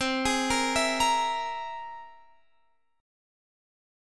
C+7 Chord (page 2)
Listen to C+7 strummed